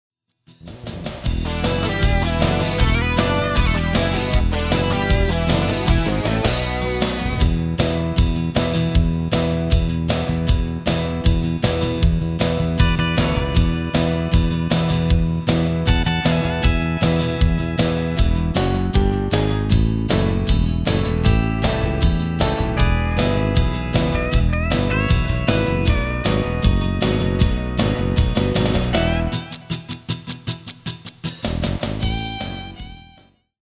Demo/Koop midifile
Genre: Country & Western
Demo's zijn eigen opnames van onze digitale arrangementen.